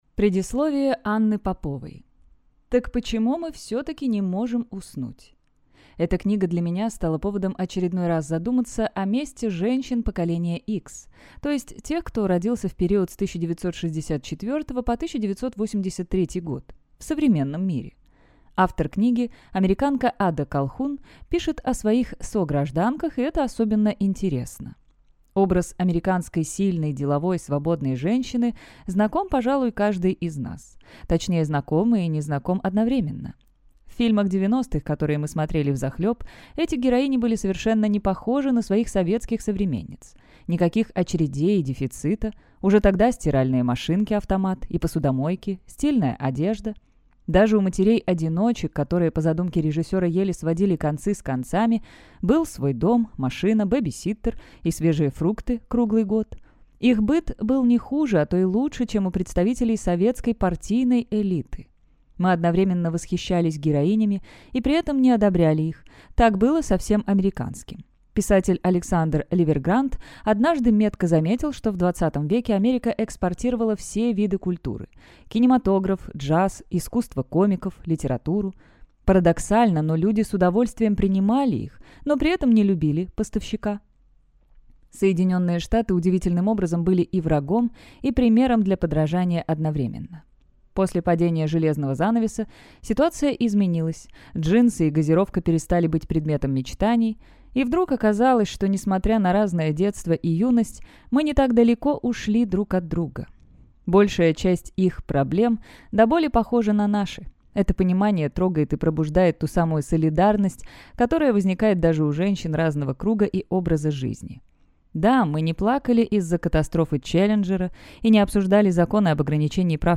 Аудиокнига О чем молчат женщины. Как кризис среднего возраста влияет на нас и почему это лучшее время для перемен | Библиотека аудиокниг